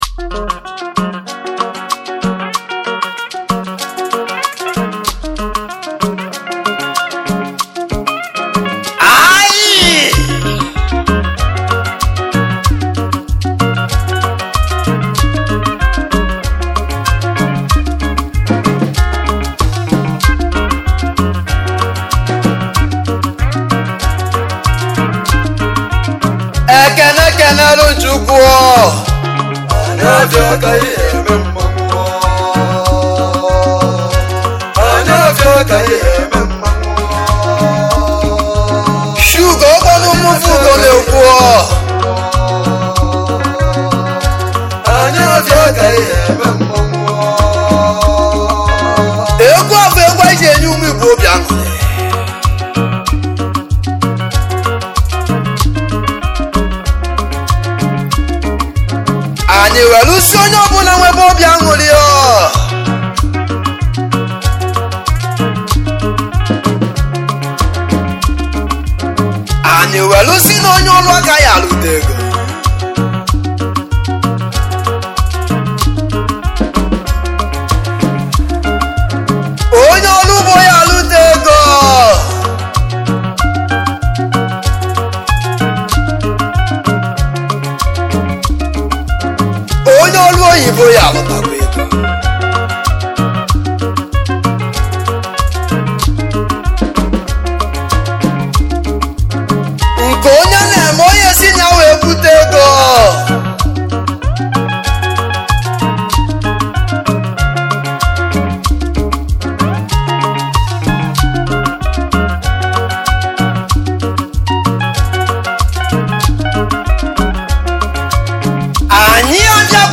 igbo highlife
highlife music band